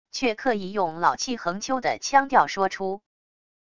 却刻意用老气横秋的腔调说出wav音频